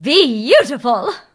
bea_kill_vo_02.ogg